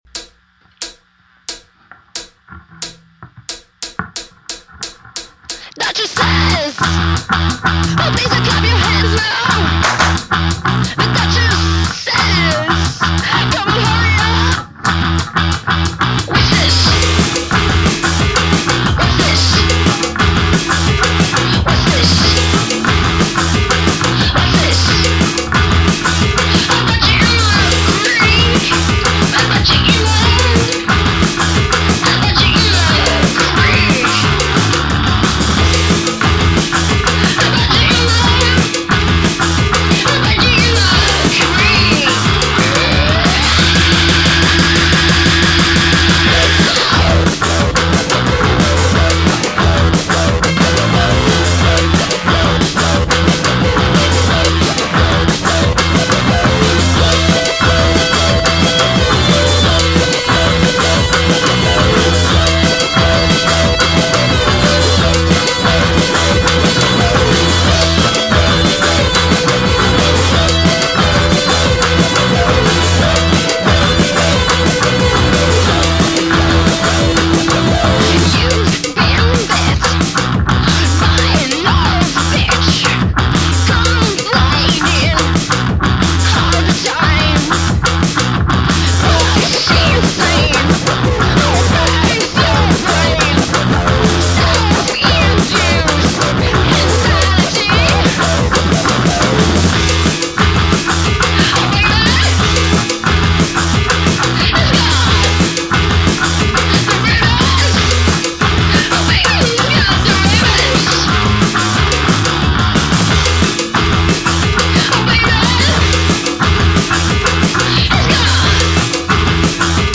De l’electro-punk énergique qui vient du Québec.